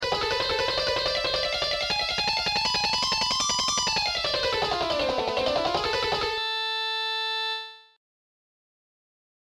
Shredding Exercises > Shredding Exercise 1
Shredding+Exercise+1.mp3